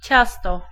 Ääntäminen
Ääntäminen Tuntematon aksentti: IPA: [ˈt͡ɕas̪t̪ɔ] Haettu sana löytyi näillä lähdekielillä: puola Käännös Ääninäyte Substantiivit 1. dough UK US 2. cake US UK 3. pie US 4. batter Suku: n .